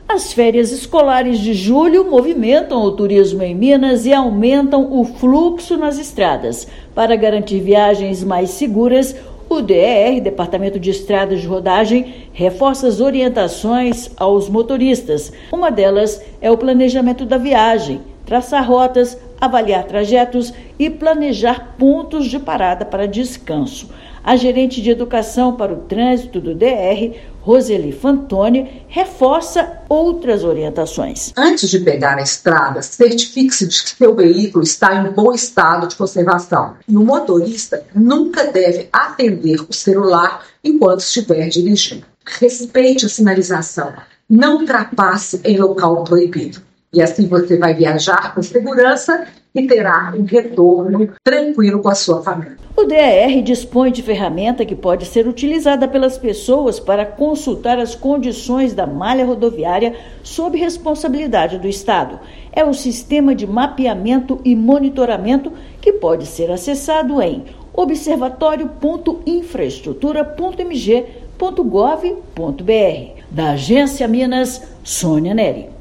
Investimentos de R$ 5,8 bilhões e trabalho do DER-MG melhoram condições das estradas para segurança viária durante o período de maior circulação. Ouça matéria de rádio.